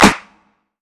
Toxic ClapSnare.wav